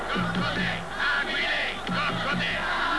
IL CORO "AQUILE COCCODE'"